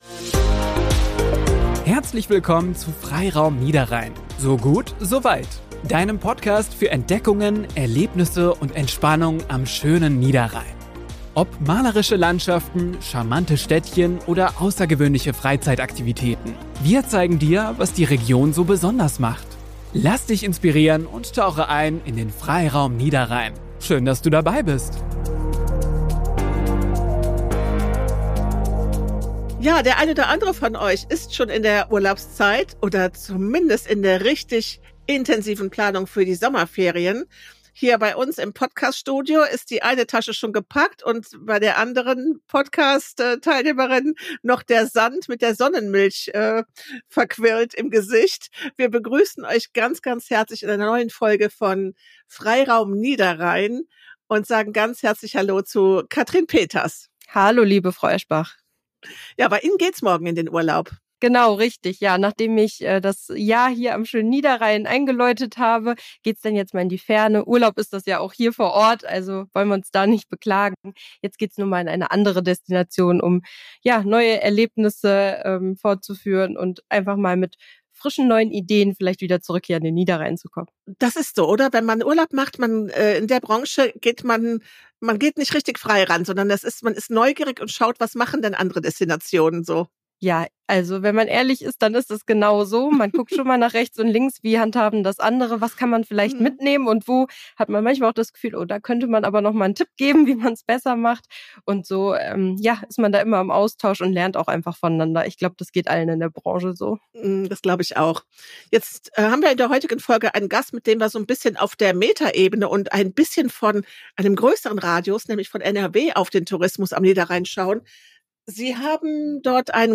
Kein Wunder, denn die Region zwischen Kleve und Heinsberg bietet einfach die perfekte Mischung aus Natur, Kultur, Aktivurlaub – wie Radfahren und Wandern – und kulinarischem Genuss. Zu Gast im Studio